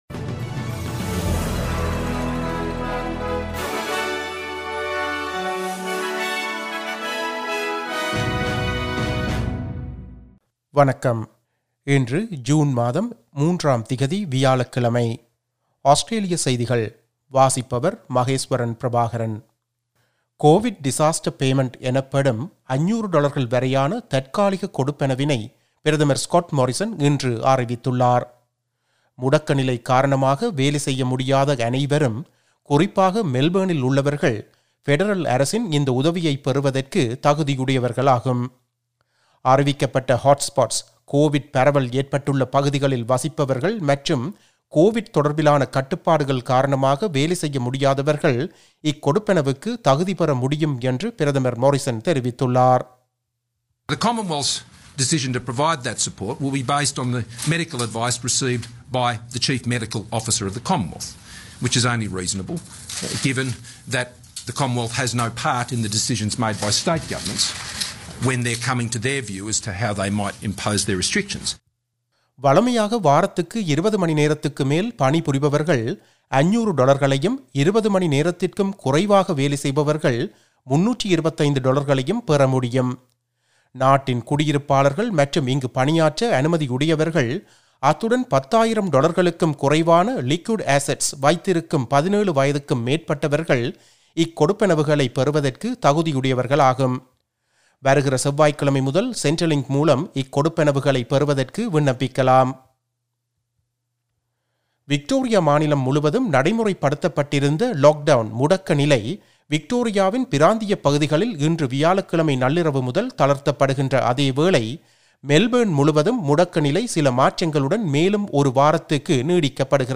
Australian news bulletin for Thursday 03 June 2021.